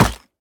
Minecraft Version Minecraft Version snapshot Latest Release | Latest Snapshot snapshot / assets / minecraft / sounds / mob / goat / impact2.ogg Compare With Compare With Latest Release | Latest Snapshot
impact2.ogg